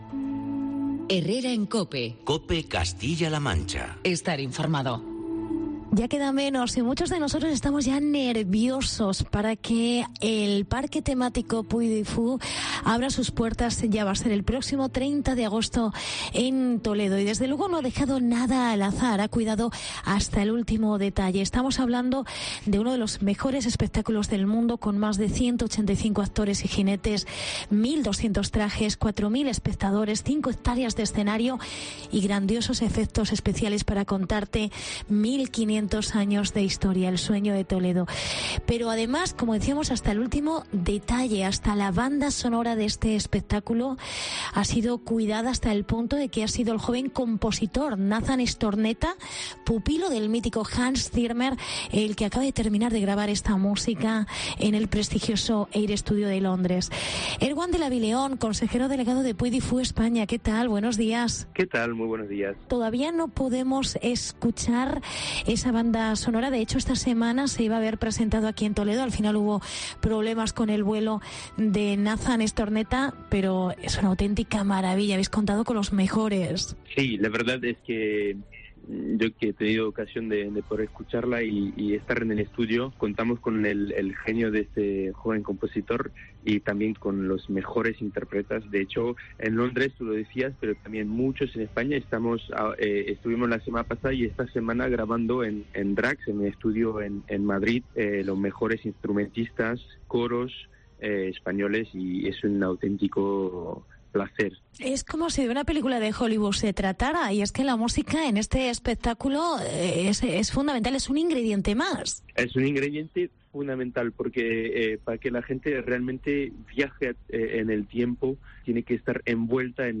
ctv-wdx-11-jul-puy-du-fou-entrevista-bso